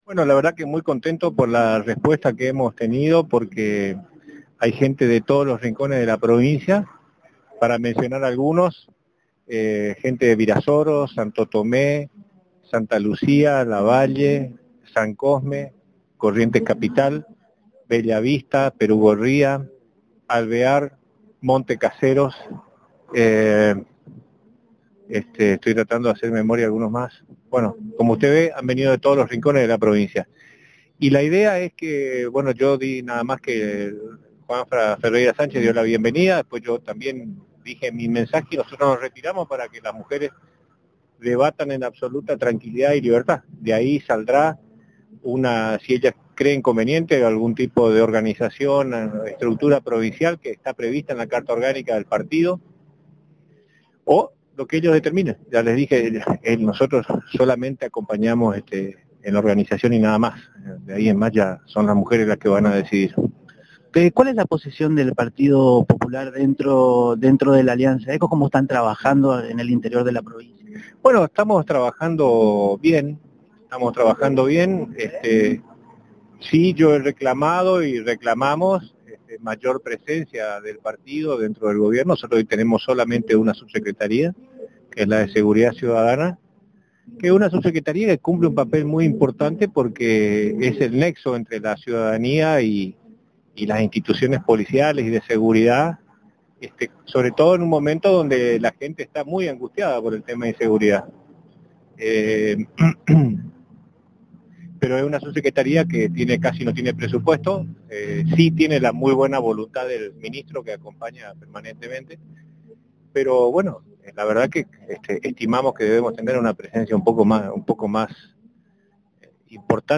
(Audio) El sábado se realizó en horas de la mañana el Encuentro Provincial de Mujeres Populares, evento organizado por el Partido Popular.
pedro_braillard_poccardenczu.mp3